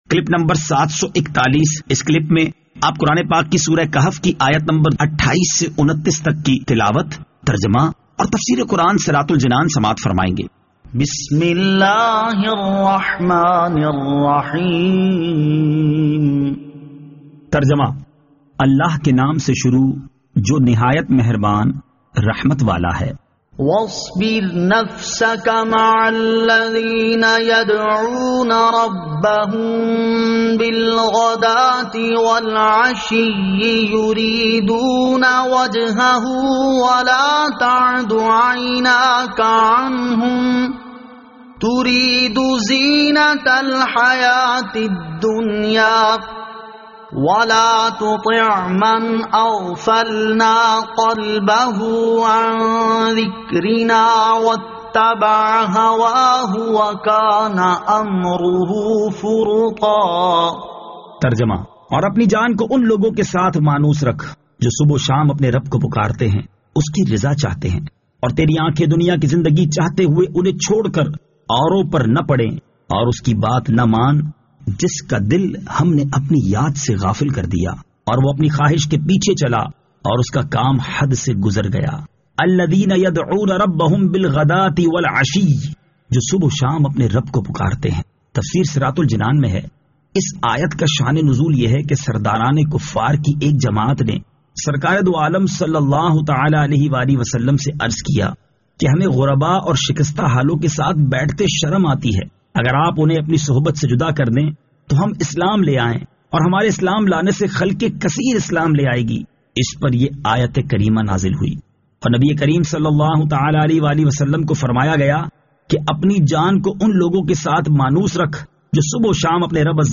Surah Al-Kahf Ayat 28 To 29 Tilawat , Tarjama , Tafseer